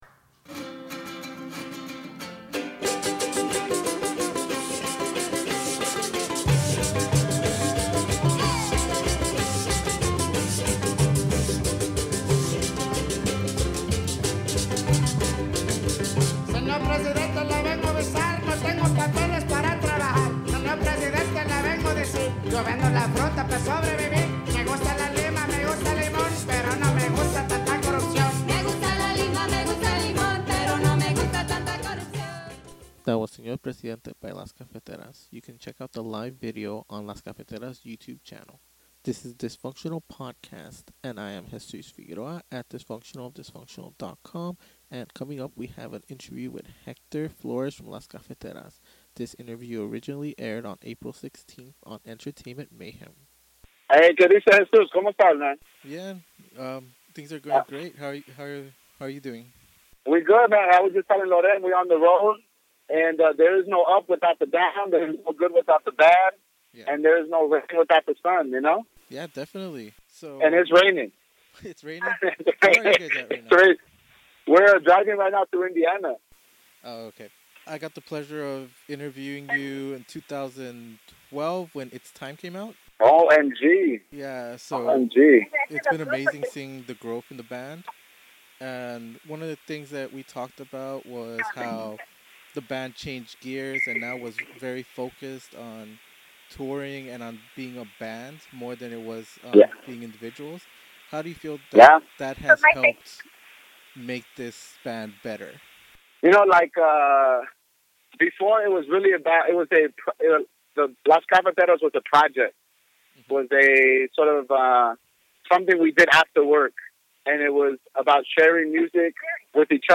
Interview
(Originally aired on Entertainment Mayhem, a Radionomy station)